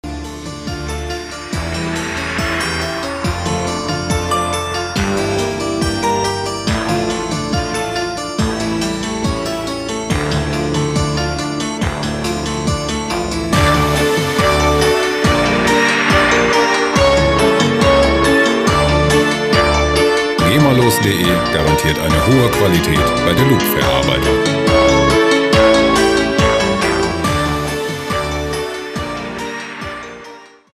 • Cembalo Pop